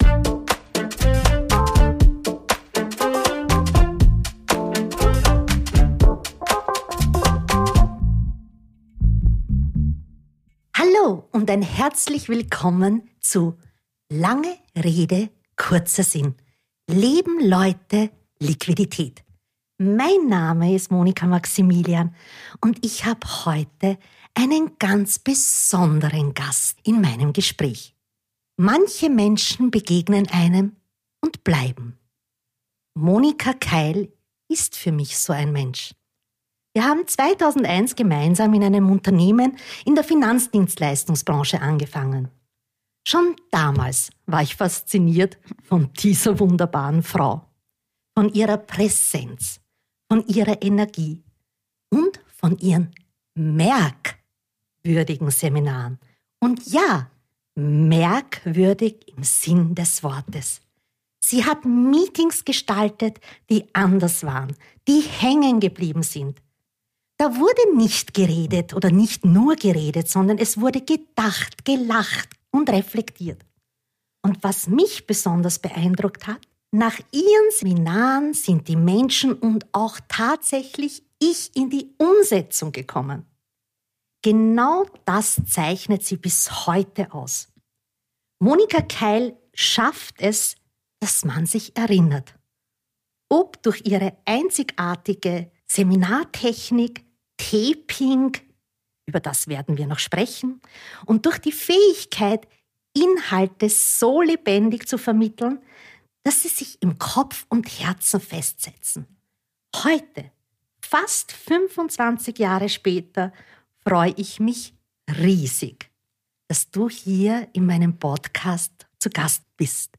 Ein Gespräch, das ruhig wirkt und lange nachhallt.